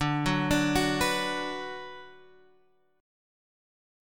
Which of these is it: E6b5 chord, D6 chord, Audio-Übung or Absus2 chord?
D6 chord